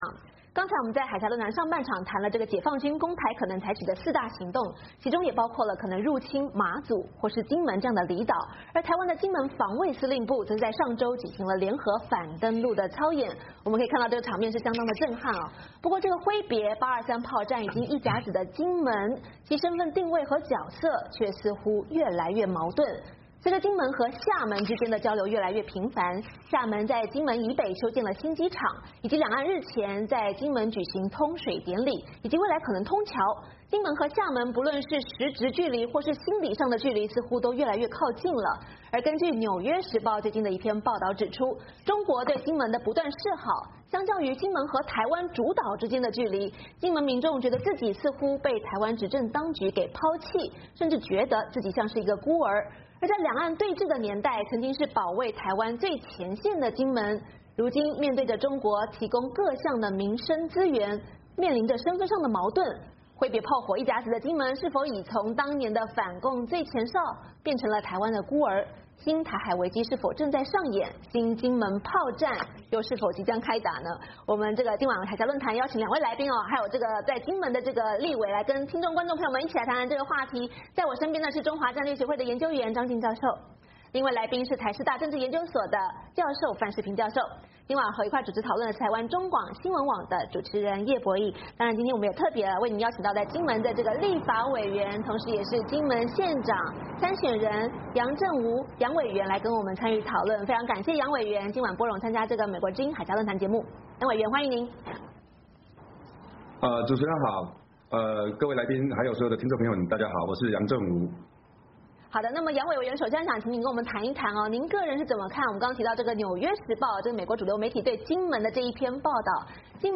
海峡论谈邀请金门立法委员、金门县长参选人杨镇浯委员谈谈金门民众怎么看两岸通水典礼以及823炮战60周年纪念台北高层未有出席？目前金厦之间的交流情况如何以及两岸通水之后中国对金门的政治影响力是否增加？